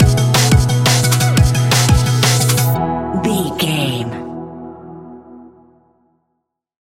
Ionian/Major
electronic
dance
techno
trance
synths
synthwave
glitch